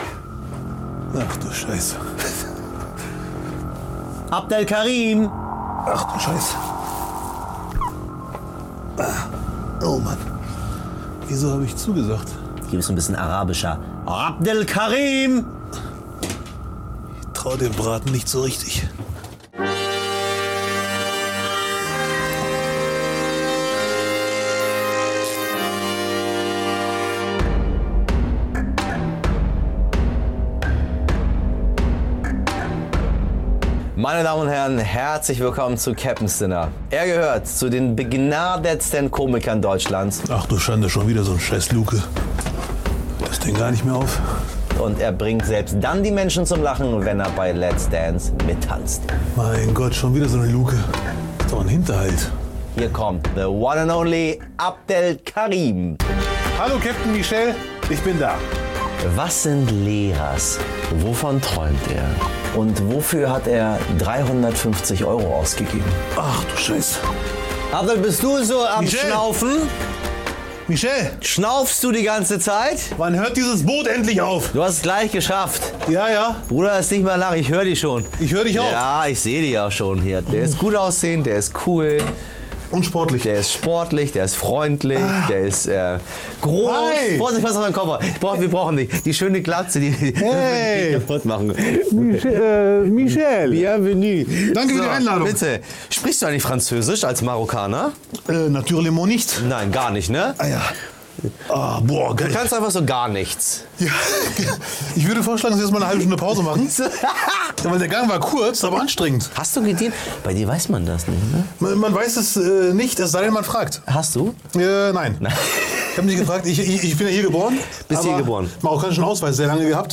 Bei Michel Abdollahi im U-Boot ist Comedian Abdelkarim zu Gast.